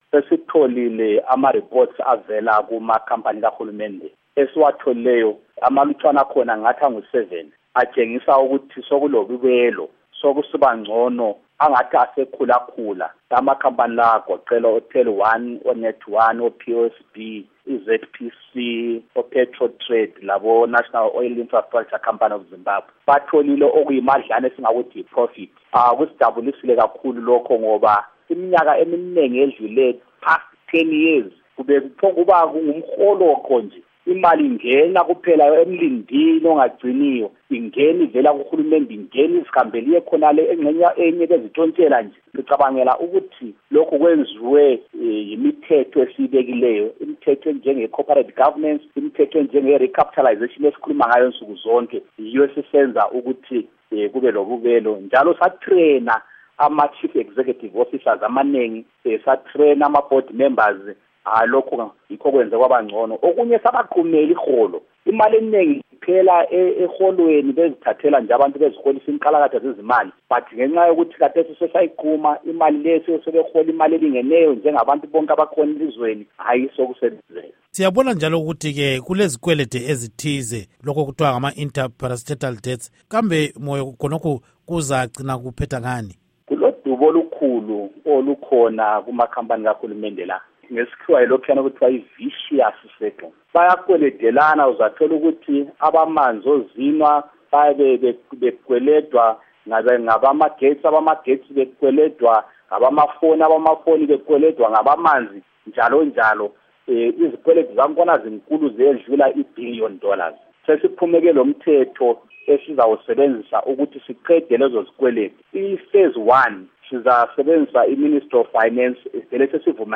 Ingxoxo Esiyenze LoMnu. Gorden Moyo